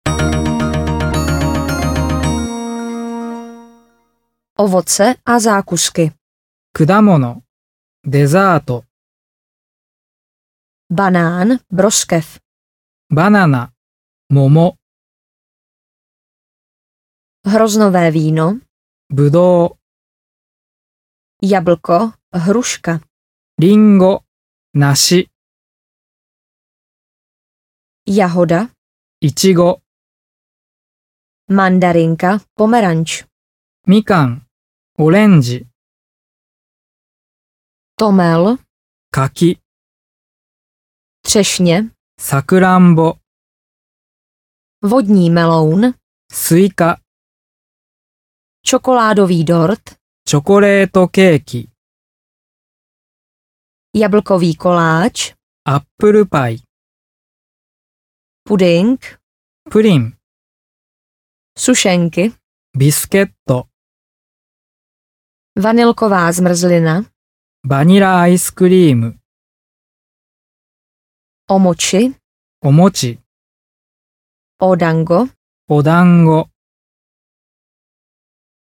Ukázka z knihy
Obsahuje 32 témat k snadnému dorozumnění, více než 500 konverzačních obratů s výslovností, samostudium formou poslechu a opakování. Dále dvojjazyčnou nahrávku rodilých mluvčích a základní informace pro turisty.Obsah: Výslovnost Všeobecné výrazy Pozdravy a představování Otázky a všeobecné dotazy Slova opačná Hotel - ubytování Restaurace Snídaně Předkrmy Polévky Maso Ryby a plody moře Přílohy Zelenina a saláty Ovoce a zákusky Nápoje Stížnosti Cestování vlakem Cestování letadlem Cestování lodí   Cestování městskou dopravou Taxi Půjčovna aut Prohlížení pamětihodností Zábava a kultura Obchody a služby Banka Pošta Telefon Datum, čas, počasí Čísla Pohotovost